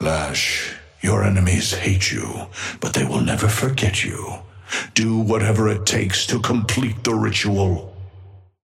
Amber Hand voice line - Lash, your enemies hate you, but they will never forget you.
Patron_male_ally_lash_start_04.mp3